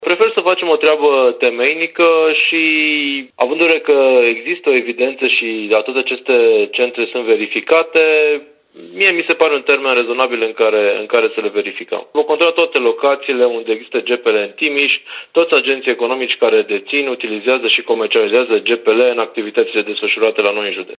Prefectul de Timiș, Mihai Ritivoiu, spune că verificările vor fi făcute de echipe mixte formate din angajați ISU, ISCIR, Agenției Județene pentru Protecția Mediului, Gărzii de Mediu, ITM, Poliției şi ai primăriilor.
Mihai-Ritivoiu-GPL-1.mp3